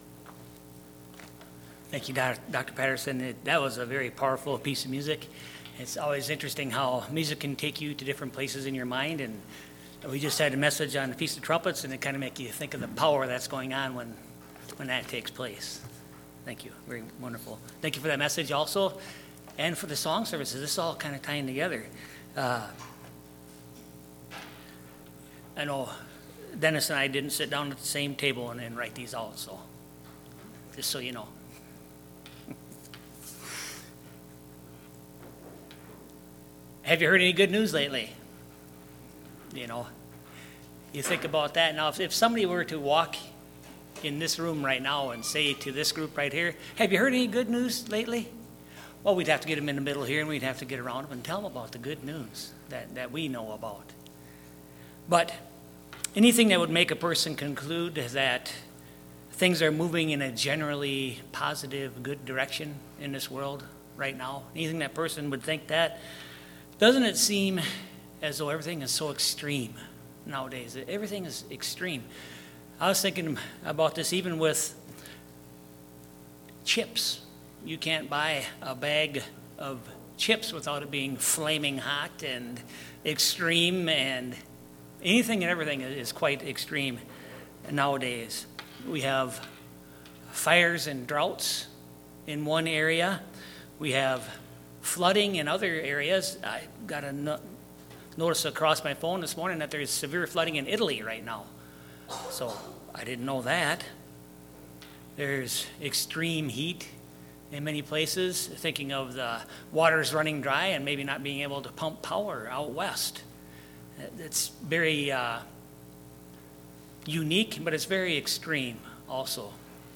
Given in Eau Claire, WI